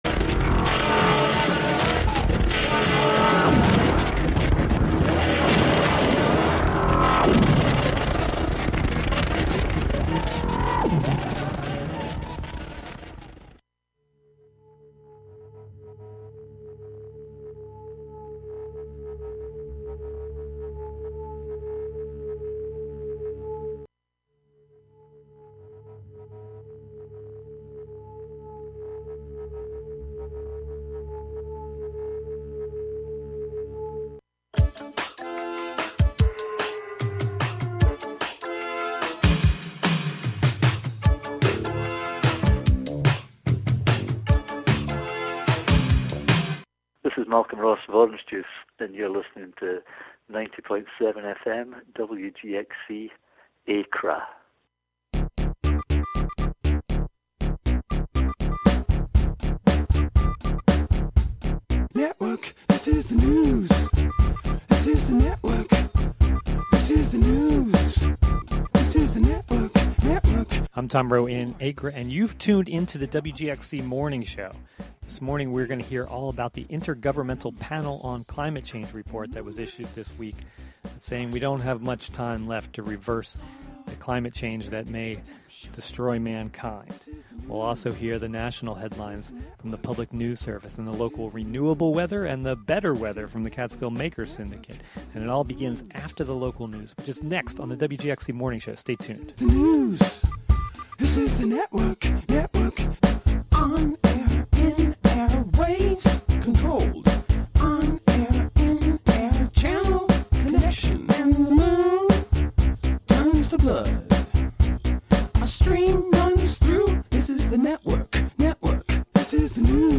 Contributions from many WGXC programmers.
The newest climate report from the Intergovernmental Panel on Climate Change is, once again, issuing a Code Red for life on the planet and calling for the world to immediately halt the burning of fossil fuels to avoid climate collapse. We hear from U.N. Secretary-General António Guterres; Inger Anderson, Executive Director of the United Nations Environmental Program; and IPCC Working Group III Co-Chair Jim Skea.